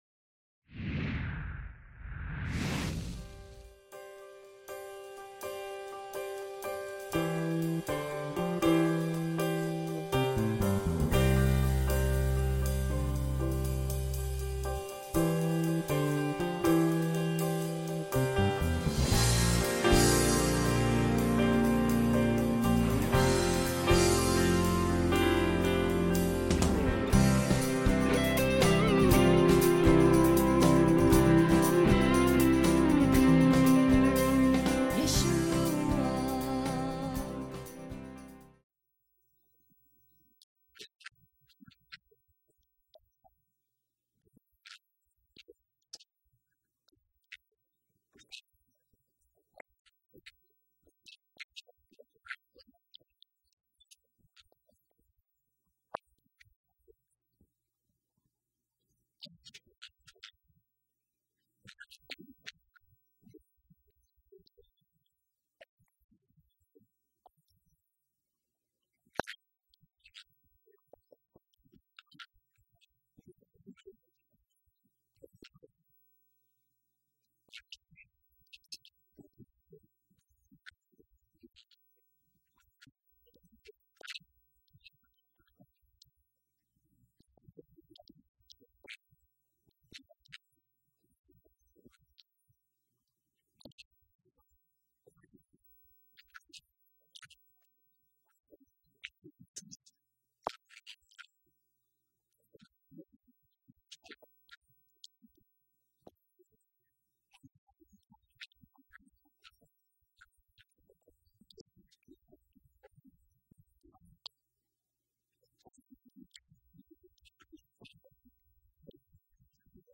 Lesson 2 Ch1 - Torah Class